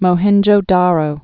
(mō-hĕnjō-därō)